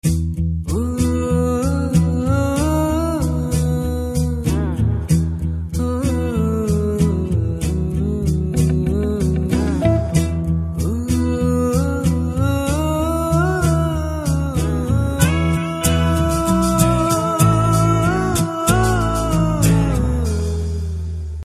CategoryTelugu Ringtones